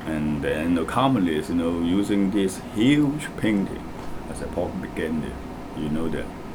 S1 = Hong Kong male S2 = Malaysian female Context: They are talking about S1's work in painting murals, particularly during his visit to Bulgaria.
S1 uses [ɑː] , as might be expected in American English.
In addition to issues regarding the vowel in the first syllable of communist , there is no clear [j] at the start of the second syllable, though this is not too surprising as the syllable is unstressed and [j] is not obligatory there; and there is no clear [t] at the end of the word, though again this is not too surprising given that it is at the end of a word final consonant cluster in an unstressed syllable, so omission of this [t] is to be expected.